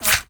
bullet_flyby_fast_14.wav